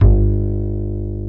EPM AKUSTI.1.wav